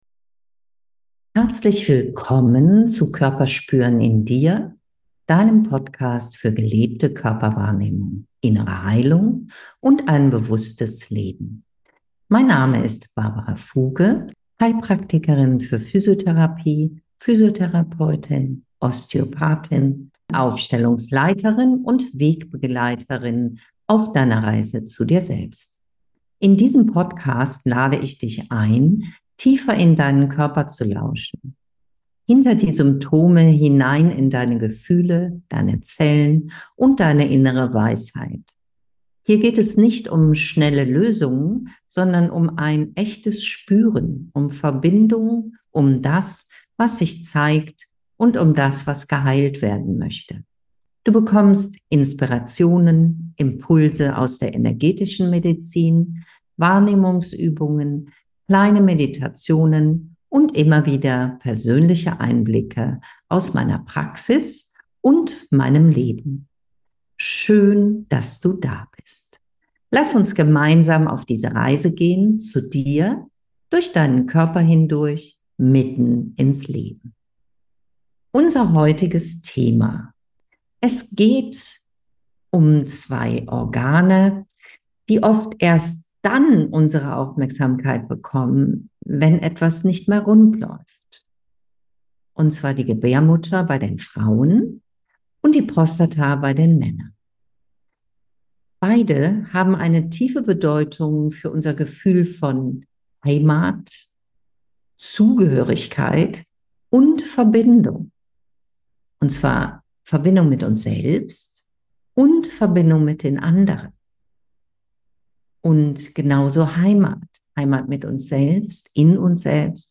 Zum Schluss führe ich dich durch eine sanfte Meditation für Männer und Frauen, die dir hilft, deinen Körper bewusster wahrzunehmen und einen Moment Ruhe und Ankommen in dir selbst zu finden.